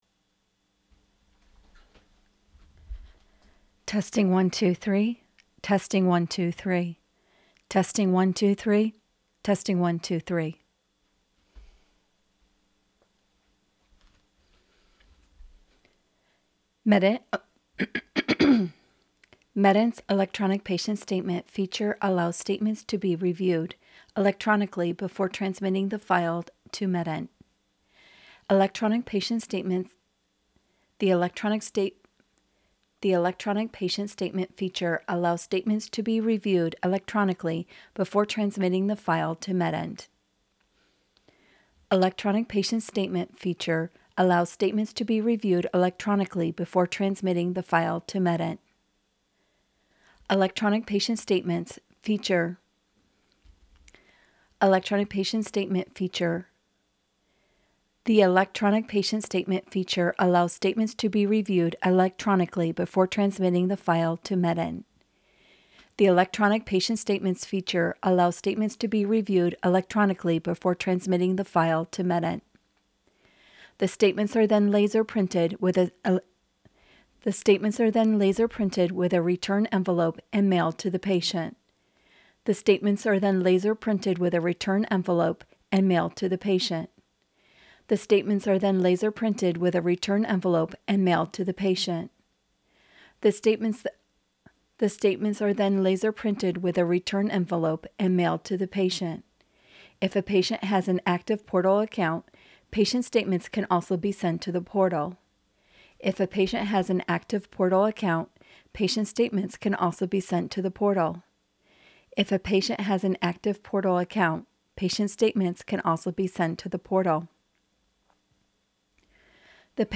Narration_Send Statements to Portal 1.m4a